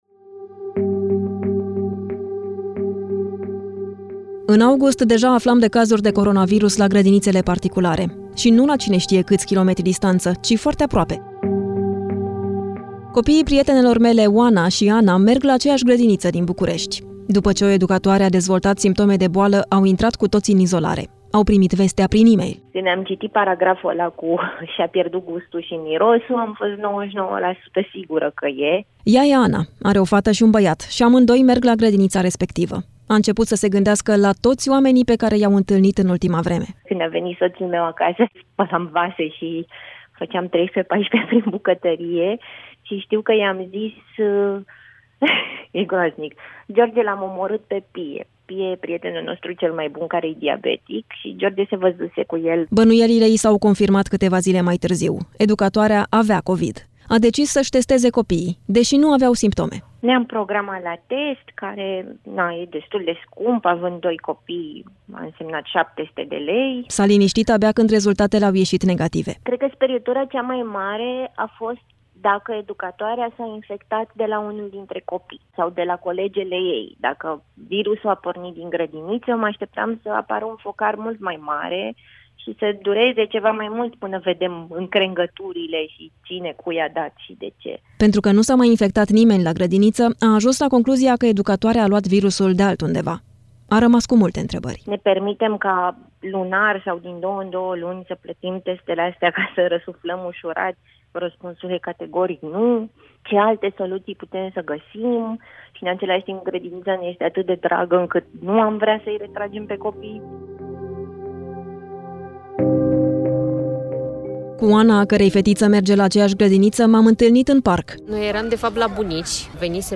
reportaj-gradinite-cu-covid-BUN.mp3